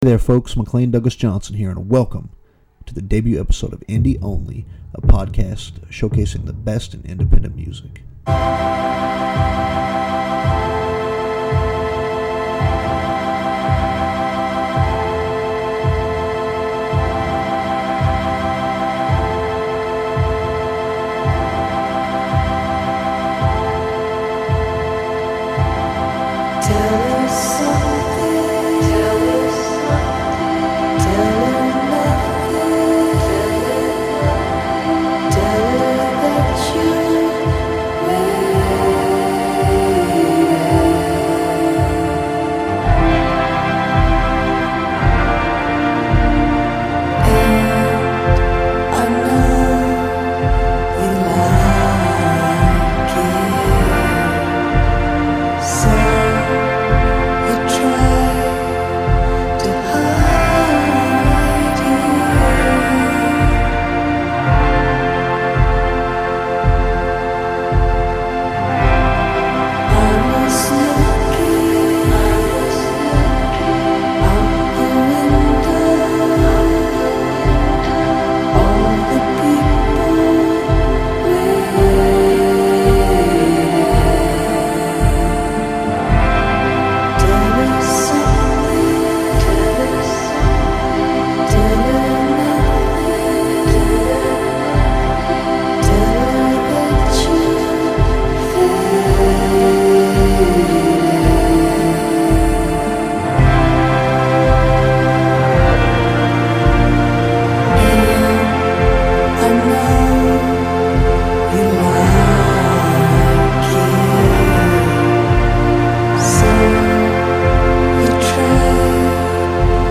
The show features some of the best in independent music.